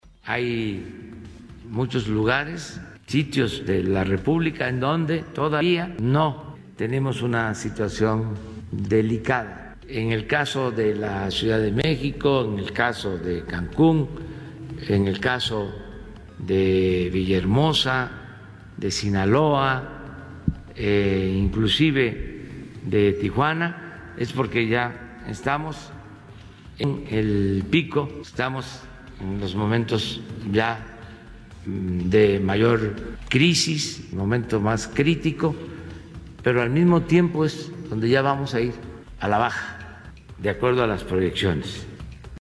«En el caso de la Ciudad de México, de Cancún, de Villahermosa, de Sinaloa, inclusive de Tijuana, ya estamos en el pico, estamos en los momentos ya de mayor crisis, en el momento más crítico, pero al mismo tiempo es donde ya vamos a ir a la baja, de acuerdo a las proyecciones que se tienen, estamos hablando de estos días, de estos 10 días», dijo en conferencia mañanera.